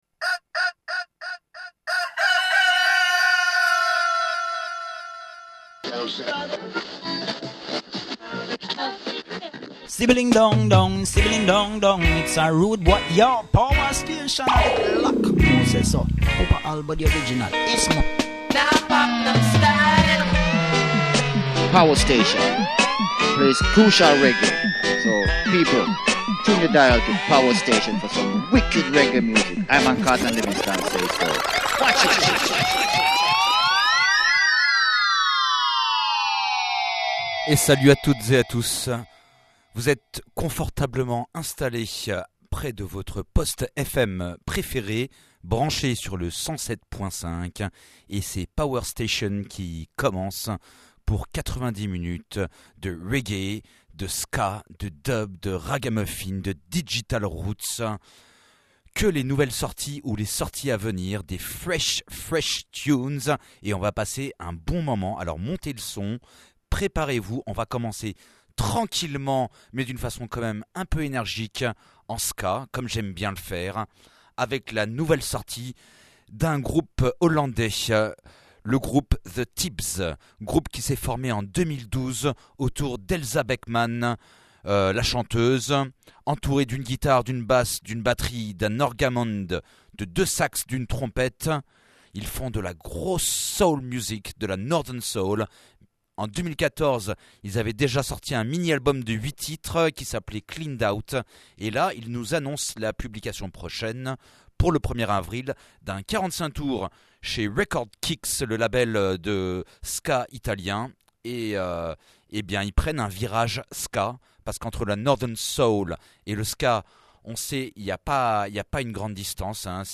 dub , reggae , ska